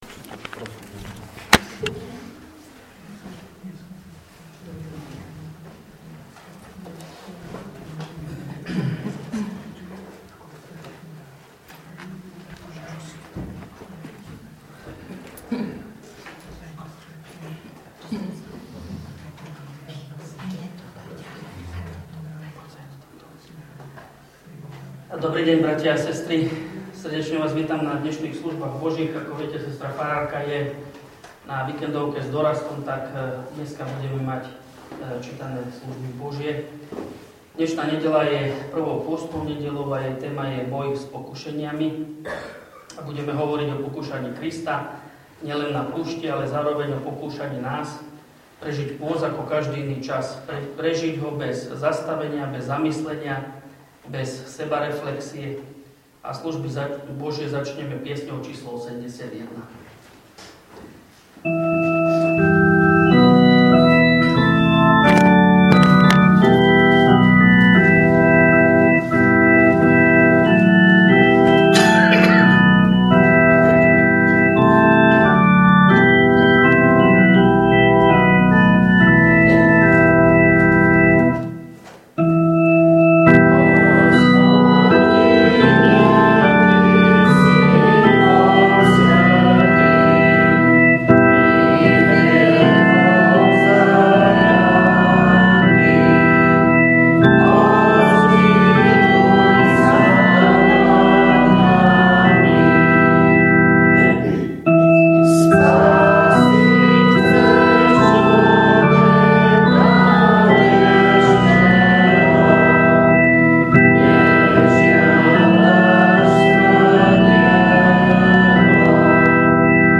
V nasledovnom článku si môžete vypočuť zvukový záznam zo služieb Božích – 1. nedeľa pôstna.